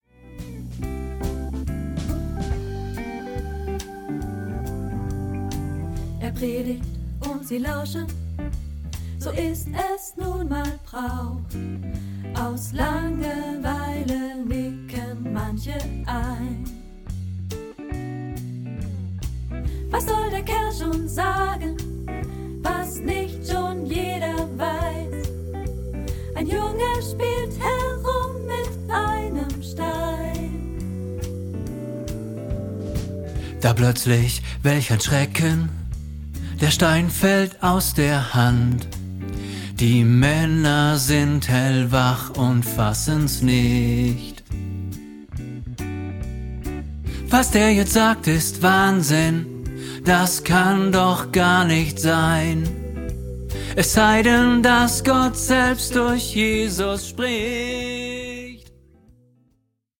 Separate Aufnahmen mit hervorgehobenen Tenor-Stimmen.